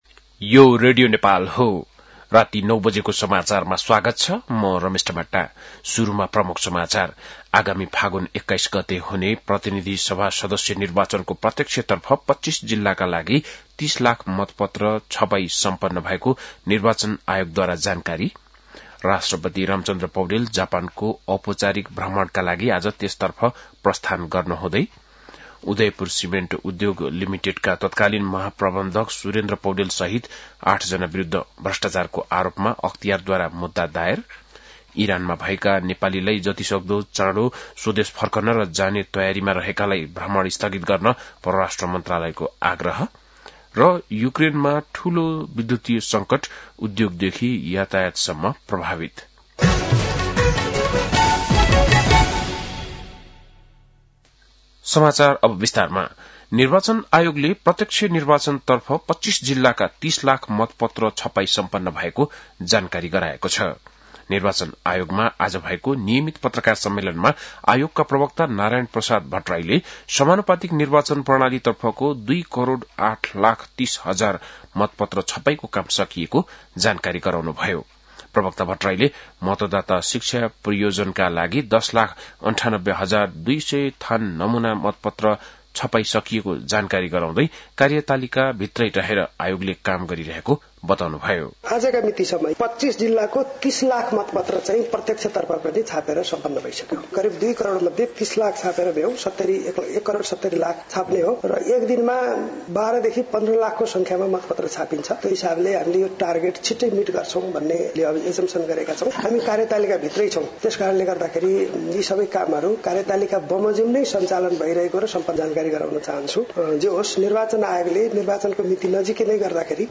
बेलुकी ९ बजेको नेपाली समाचार : १८ माघ , २०८२
9.-pm-nepali-news-.mp3